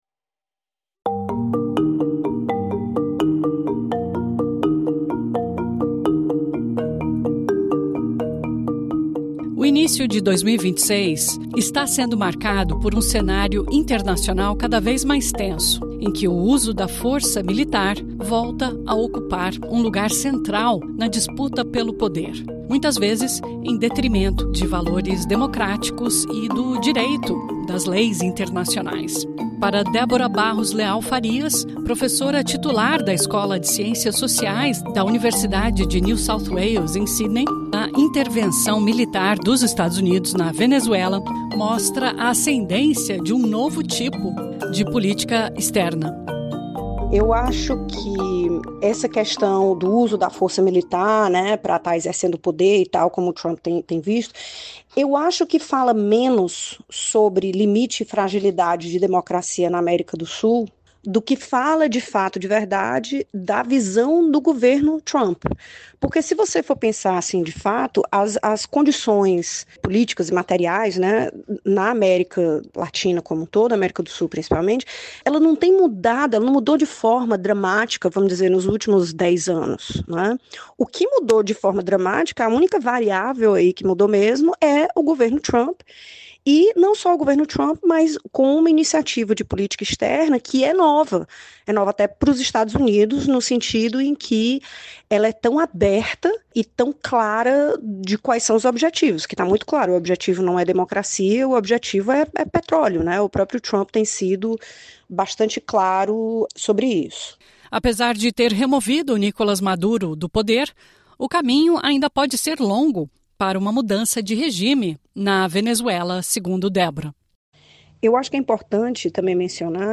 Pontos princiais Cientista política e professora